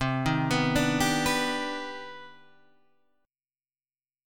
CmM9 Chord
Listen to CmM9 strummed